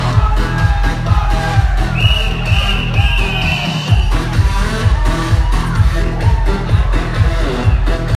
노래 찾아주세요 다 녹음 잘되서 잘 찾아줄수있으실꺼에요 ..ㅠ